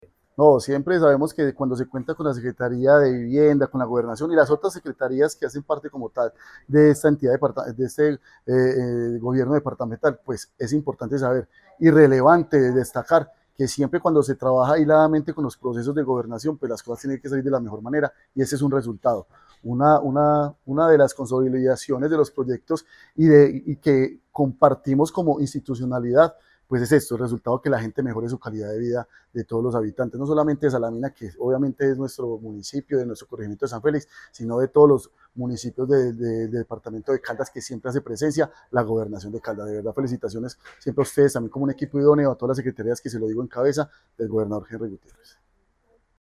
Manuel Fermín Giraldo Gutiérrez, alcalde de Salamina.
MANUEL-FERMIN-GIRALDO-G.-Alcalde-Salamina-Entrega-vivienda-rural.mp3